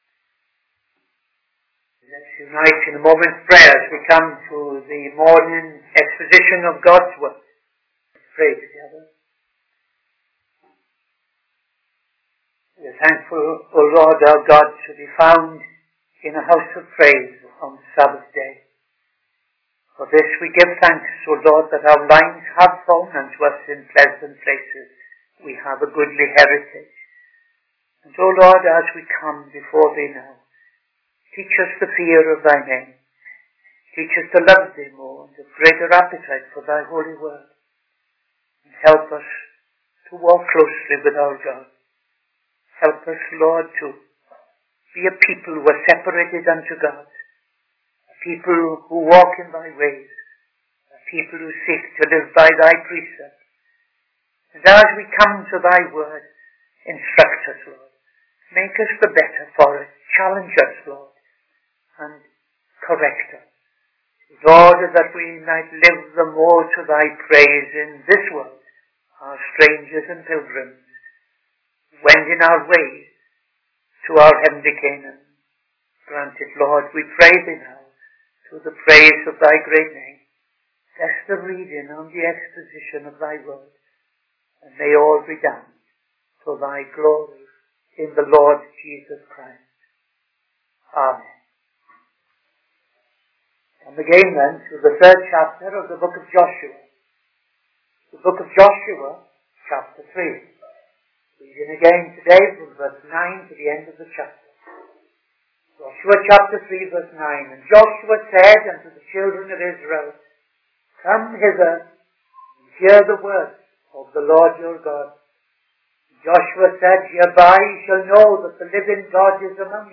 Midday Sermon - TFCChurch
Midday Sermon 7th December 2025